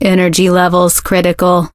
max_die_vo_04.ogg